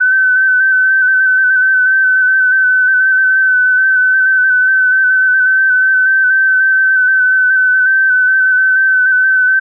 FST4, 15-sec mode
FST4-15.ogg